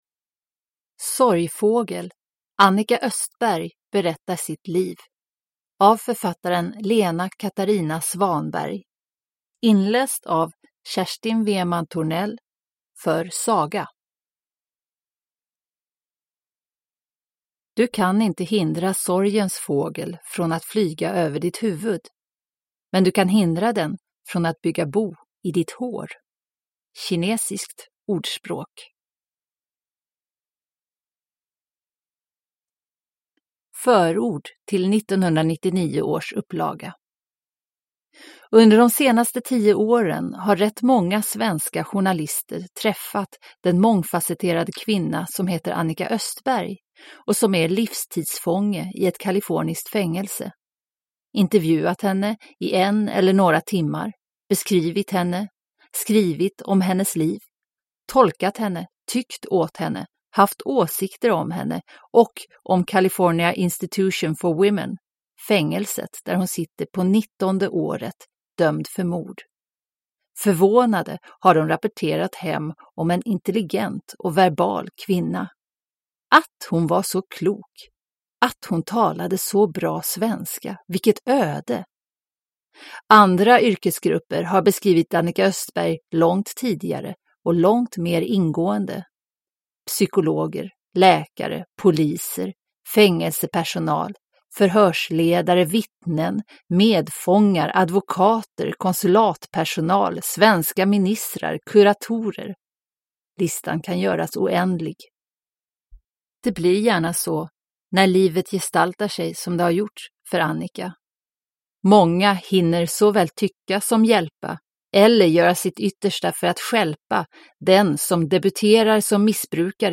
Sorgfågel / Ljudbok